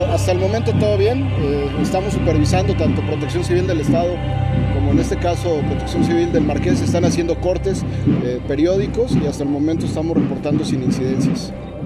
Iovan Elías Pérez, Secretario de Seguridad Ciudadana, reporta que hasta el momento no se han registrado incidentes en los viacrucis en Querétaro.